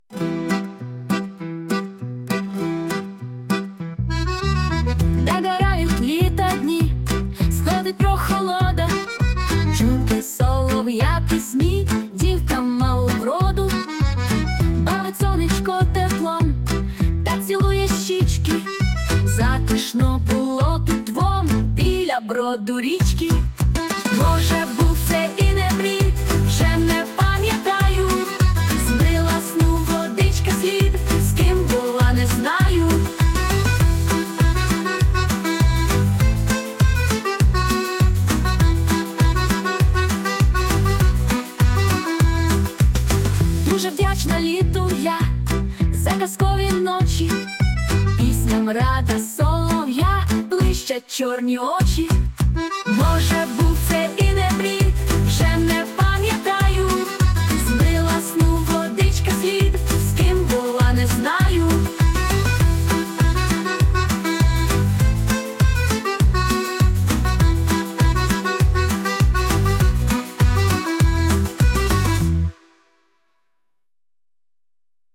Музичний супровід створено за допомогою  SUNO AI
СТИЛЬОВІ ЖАНРИ: Ліричний
12 12 12 Чудова пісня, так чарівно туди вплітаються звуки баяна! 39 39 39 hi